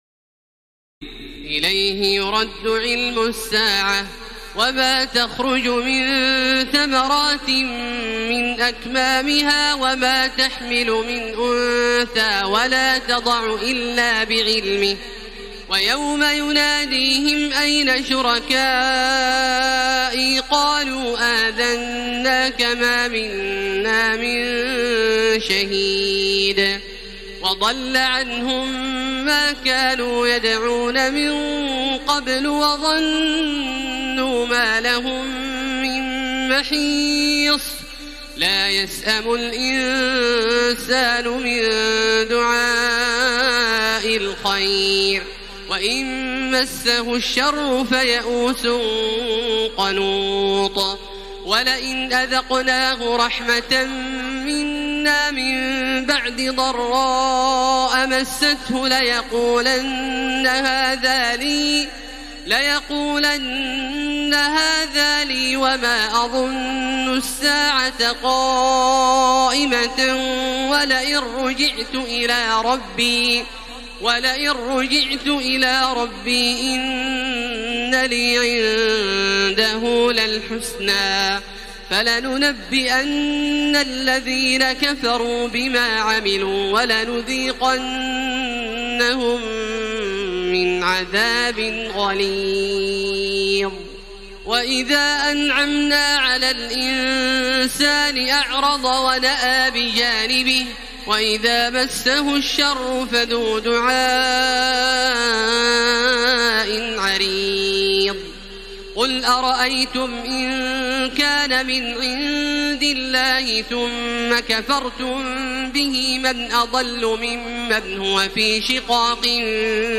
تراويح ليلة 24 رمضان 1433هـ من سور فصلت (47-54) و الشورى و الزخرف (1-25) Taraweeh 24 st night Ramadan 1433H from Surah Fussilat and Ash-Shura and Az-Zukhruf > تراويح الحرم المكي عام 1433 🕋 > التراويح - تلاوات الحرمين